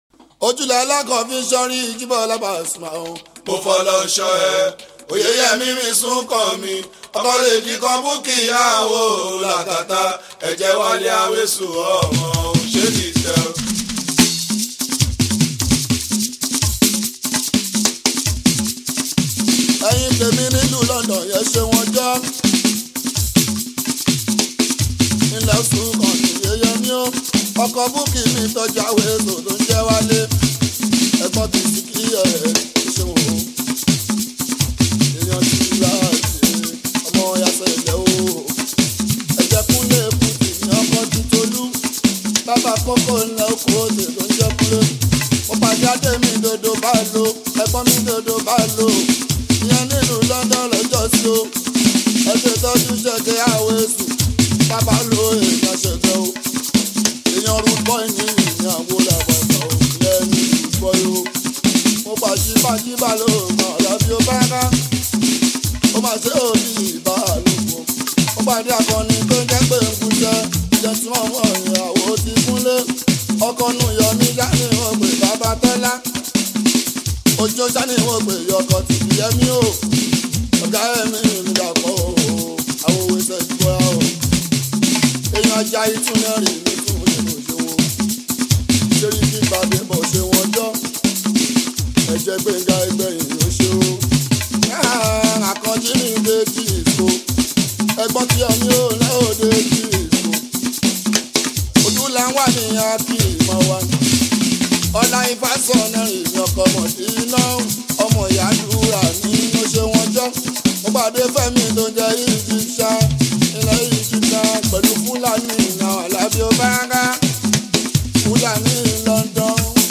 Nigerian Yoruba Fuji track
Yoruba Fuji Sounds
be ready to dance to the beats